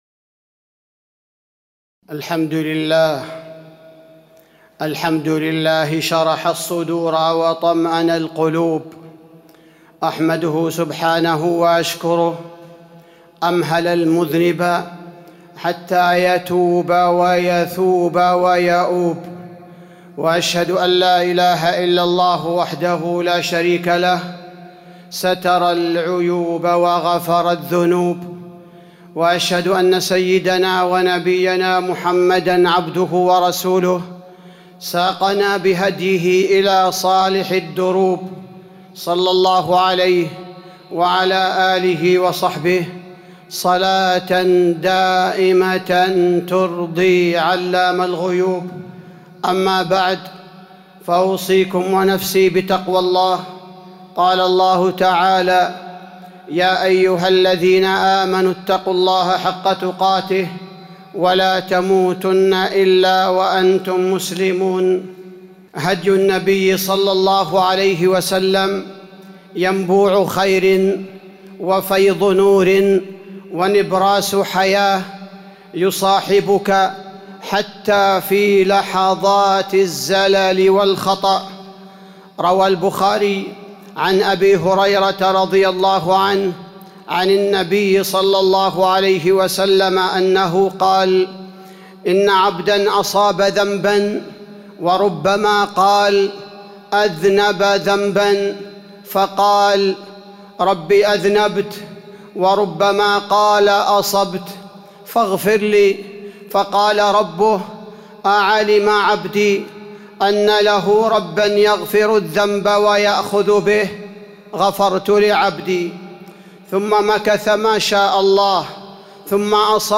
تاريخ النشر ١٩ ربيع الثاني ١٤٤٢ هـ المكان: المسجد النبوي الشيخ: فضيلة الشيخ عبدالباري الثبيتي فضيلة الشيخ عبدالباري الثبيتي رحلة مع الذنب